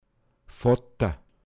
pinzgauer mundart
Våta, m. Vater
Vååda, m. (despektierlich);